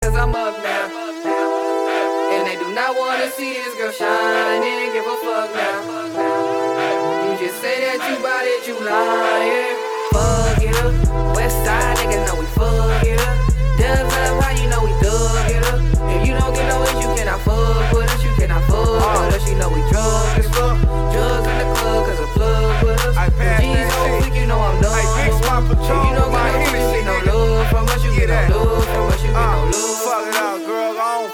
• Качество: 320, Stereo
Хип-хоп
качающие
Rap